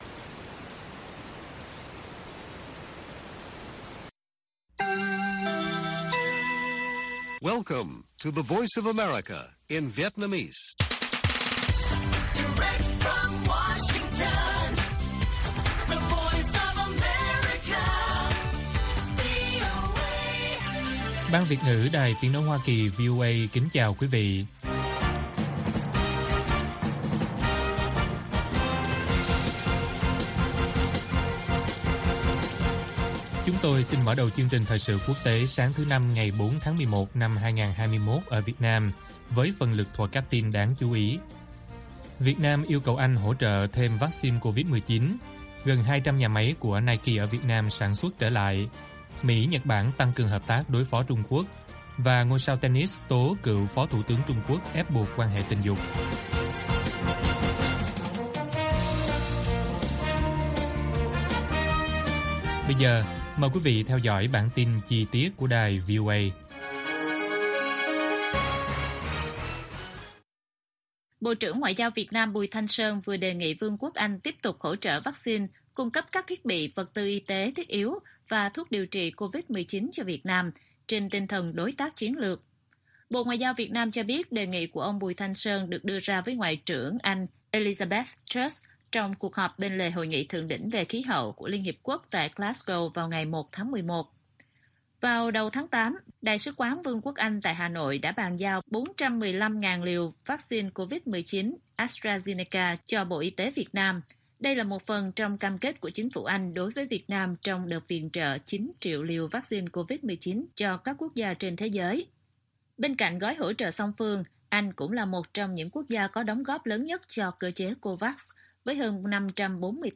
Bản tin VOA ngày 4/11/2021